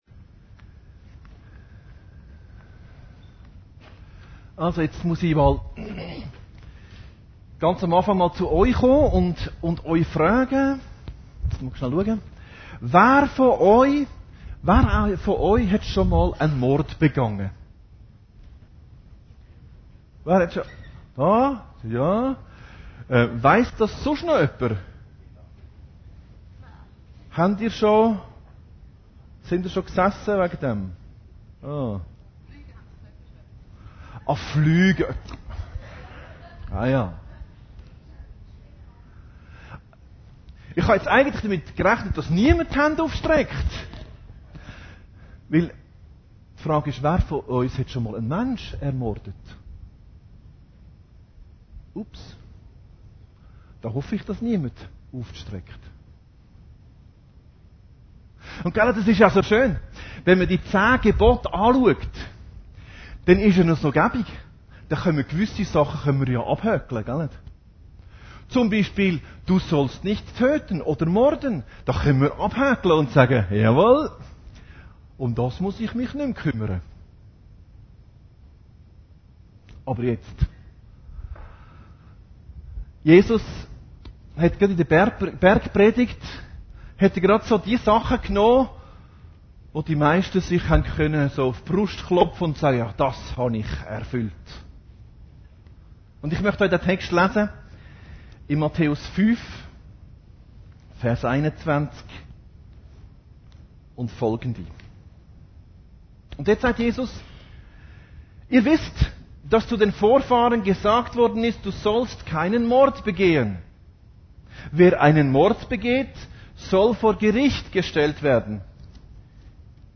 Predigten Heilsarmee Aargau Süd – Ich und mein Bruder